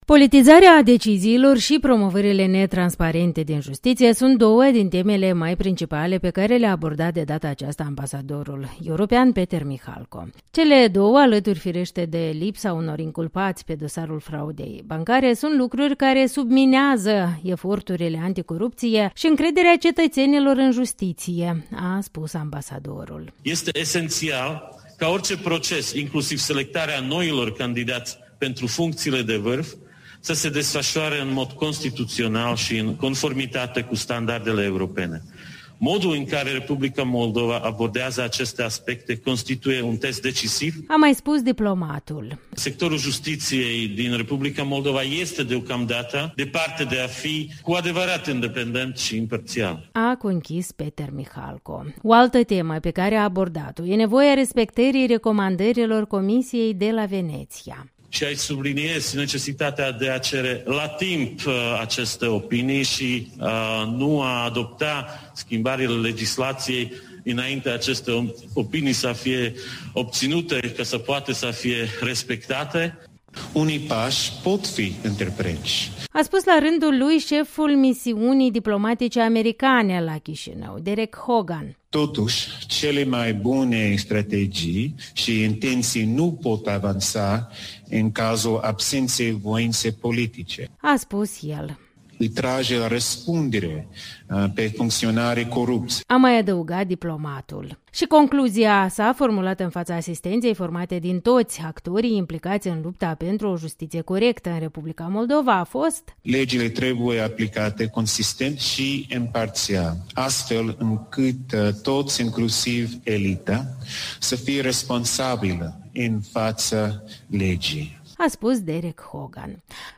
Ambasadorul european la Chișinău, Peter Michalko, a spus joi la Chișinău, la un forum dedicat justiției, că eforturile de diminuare a corupției în R. Moldova sunt subminate de decizii politizate și promovări netransparente în funcții-cheie în justiție. Ambasadorul american, Dereck Hogan, care la fel a avut o alocuțiune la reuniunea de două zile, a vorbit mai ales despre nevoia de voință politică pentru reforme reale în instituțiile care luptă cu corupția.